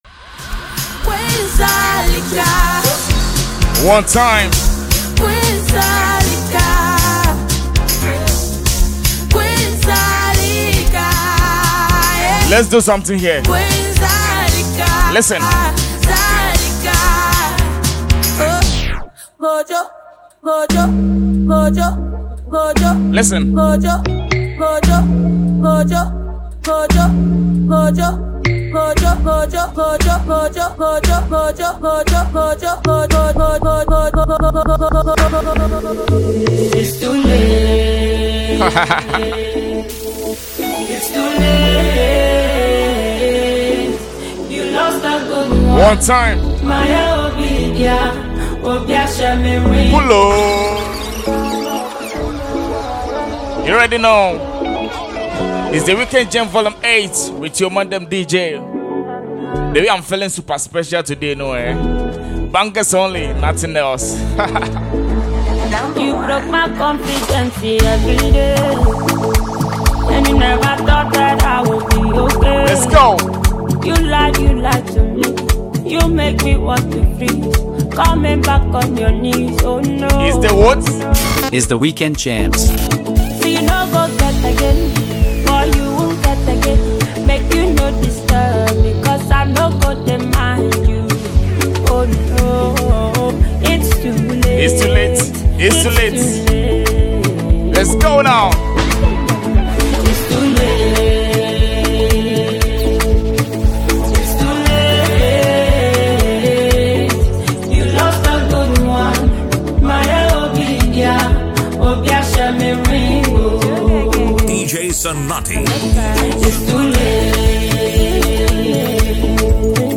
Afrobeats, highlife, and dancehall music
Genre: Mixtape